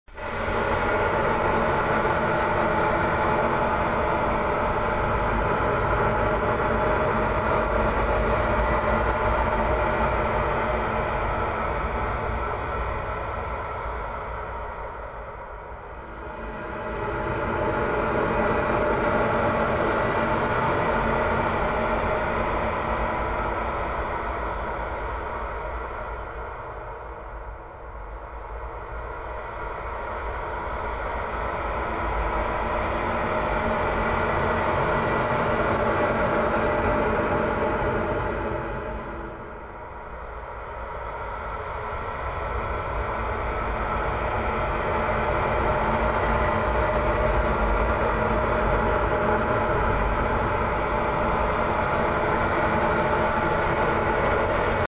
ambient track